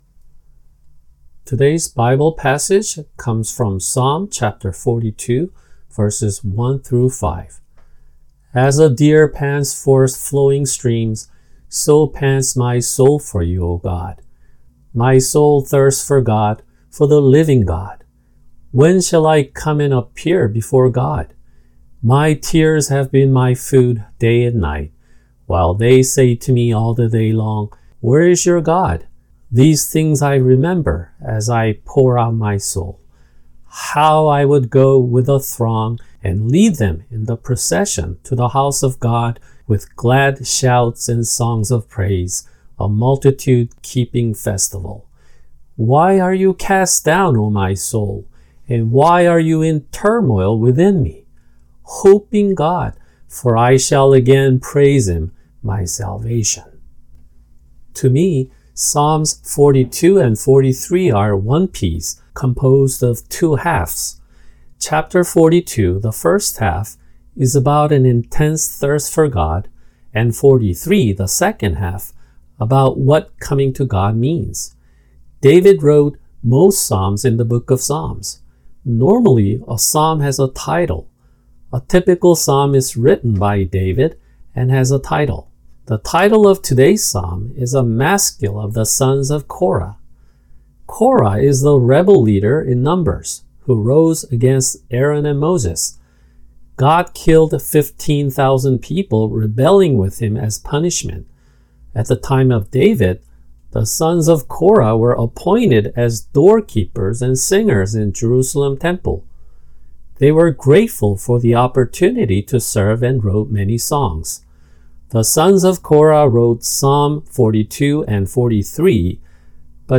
[English Translation – Audio] Psalms 42:1-5